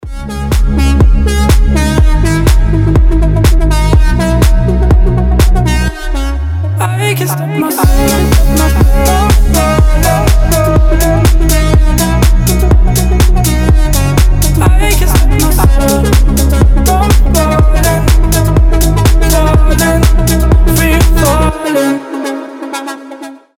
• Качество: 320, Stereo
deep house
атмосферные
Electronic